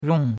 rong4.mp3